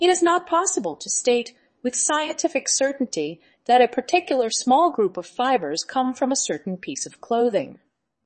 tortoise-tts - (A fork of) a multi-voice TTS system trained with an emphasis on quality
tortoise.mp3